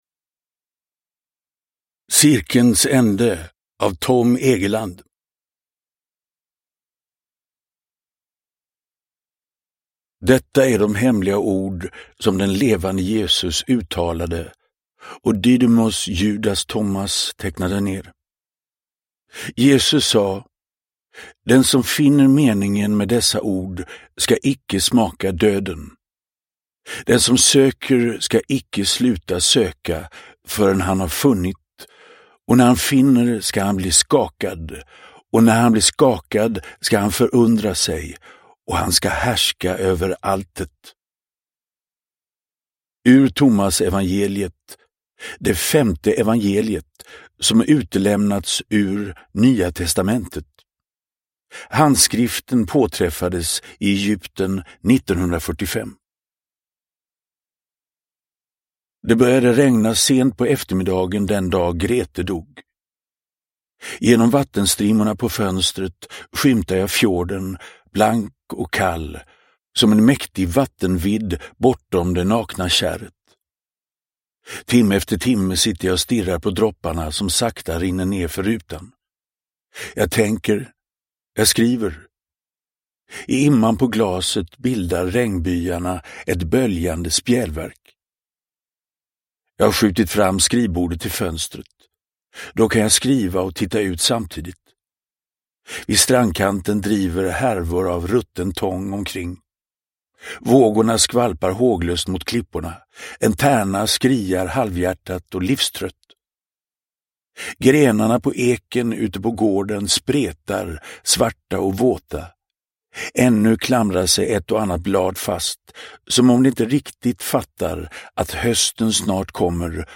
Cirkelns ände – Ljudbok – Laddas ner